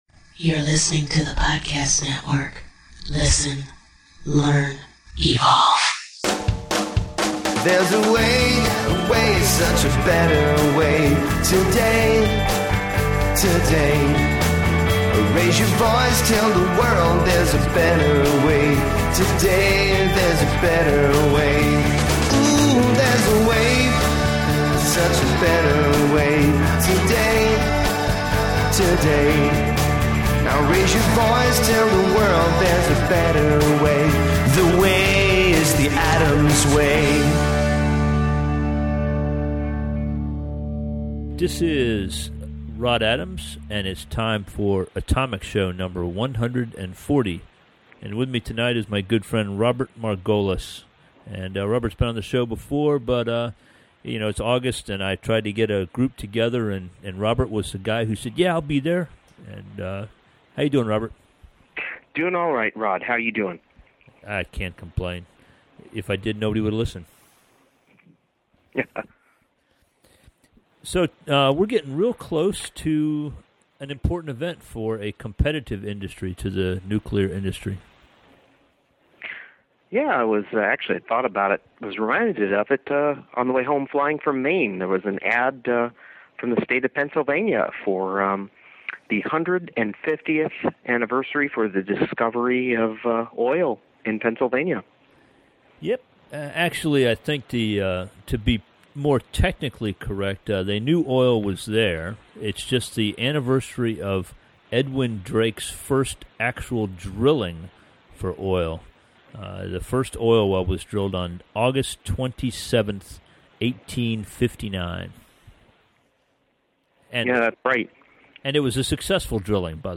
engage in a panel of two